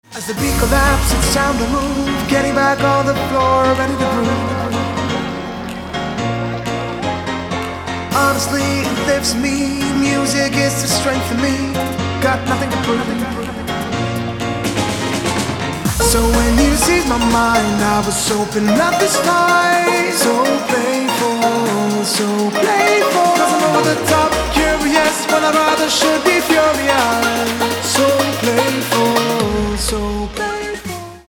• Качество: 256, Stereo
мужской вокал
dance
Electronic
club
house
vocal